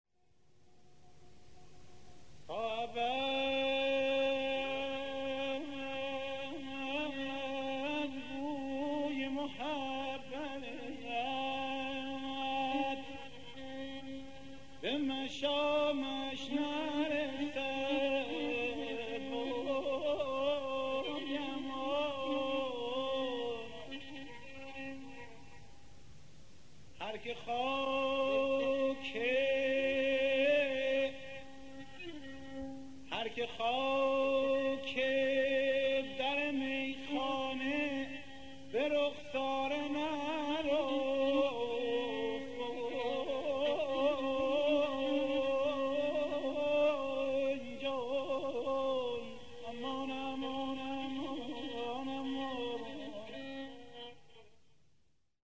سپس وارد گوشه دلکش و قرچه می شود: